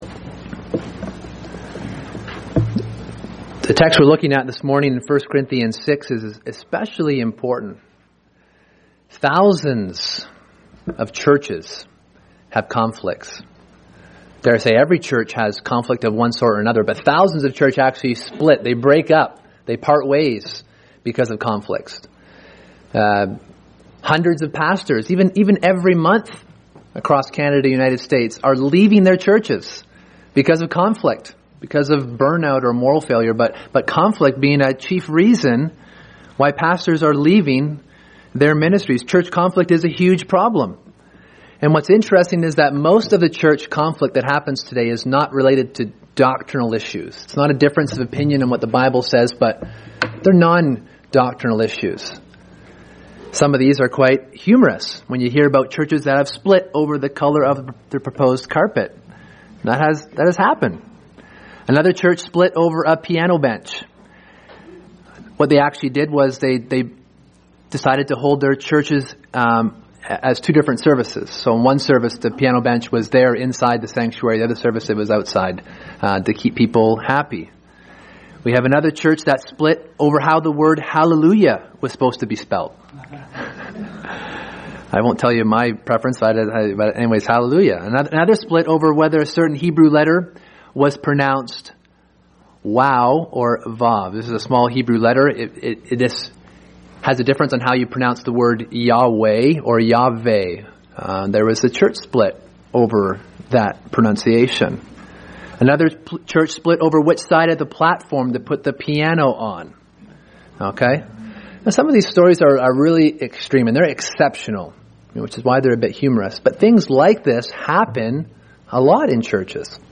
Sermon: Handling Church Grievances